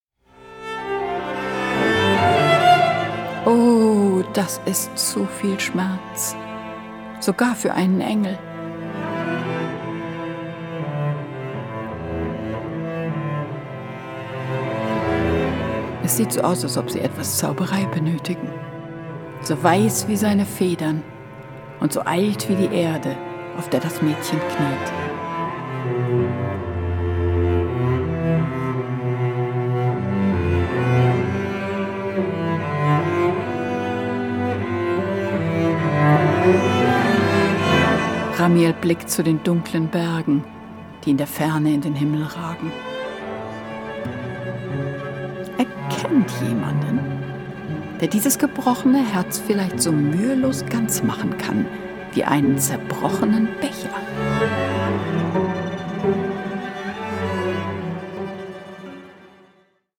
SANFTE, EINDRINGLICHE GEISTER, TRÄUME UND SCHLAFLIEDER
mitreißende Gesänge
begleitet von den üppigen Cellos